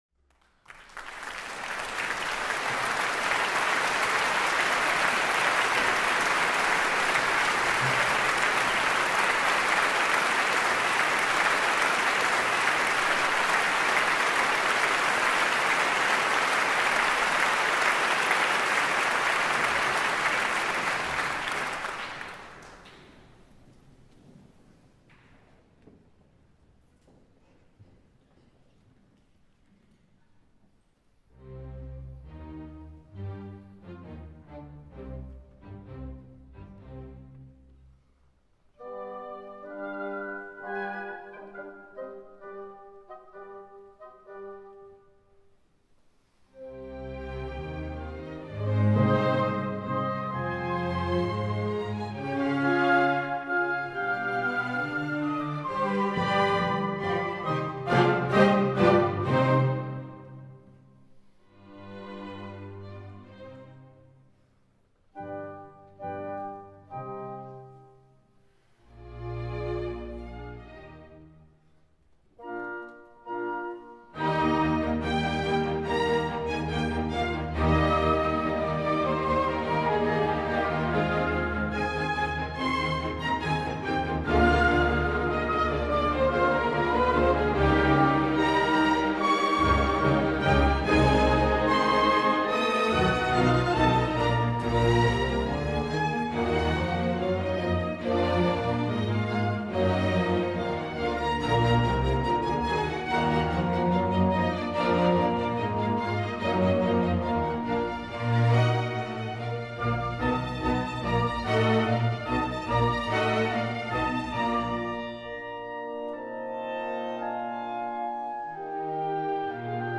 Il fut un des pianistes les plus impressionnants du XXème et du début du XXIème siècle.
donné en 2005 au festival de Lucerne
Ecoutez l'entrée du piano dans le largo qui suit le premier mouvement.
ALFRED BRENDEL - Beethoven Piano Concerto 3 ...YouTube·GreatPerformers1·3 mars 2023YouTube Quelques publicités apparaitront, désolé on n'y peut rien ... que vous pourrez au bout de quelques secondes faire disparaître en cliquant sur Ignorer .